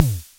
KR55B vintage analog drum machine » KR55 the Latin mind 120
描述：The KR55B was manufactured by Korg in 1980. Analog; so I did my best to set the dial at 120
标签： analog drummachine kr55b vintage
声道立体声